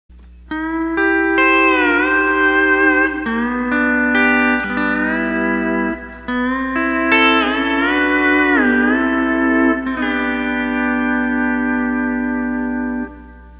Steel Guitar Tab Page 11 (E9th 3RD Pedal)
Tab502 - Basic Minor Chords Tab